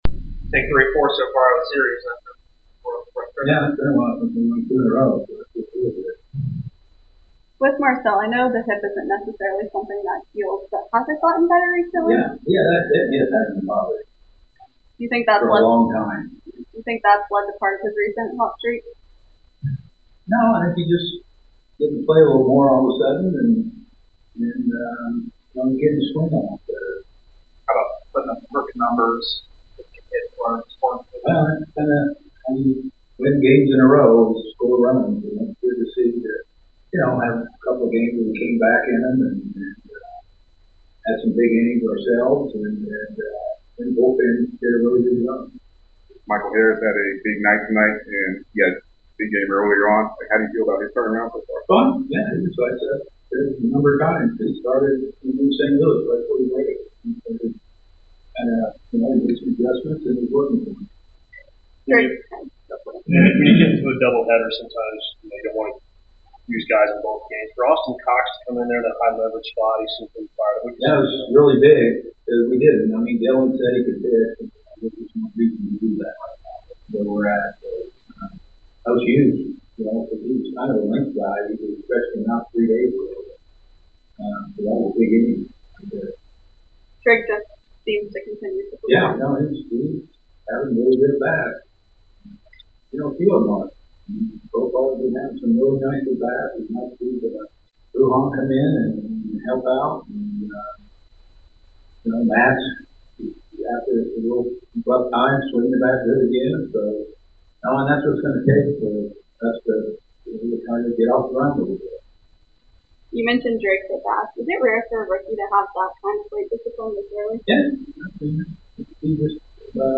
Atlanta Braves Manager Brian Snitker Postgame Interview after defeating the Miami Marlins at Truist Park.